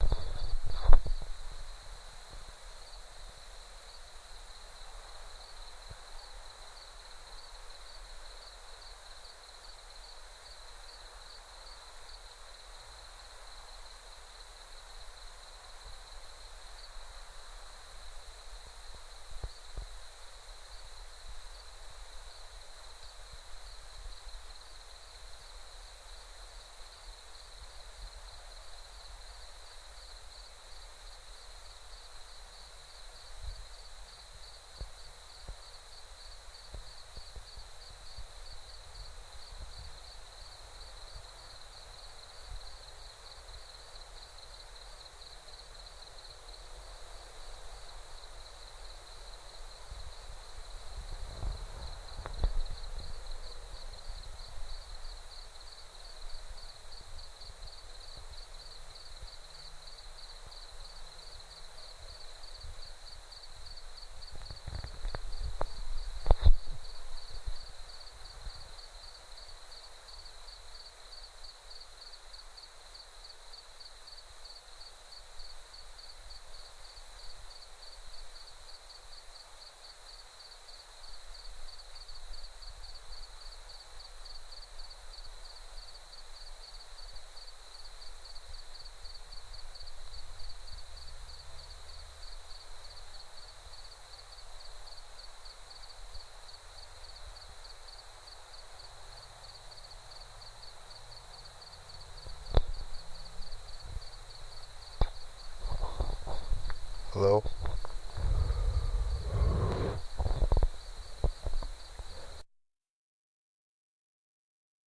ftn2附近的蟋蟀
描述：晚上在喷泉附近为蟋蟀录音的时间更长
Tag: 蟋蟀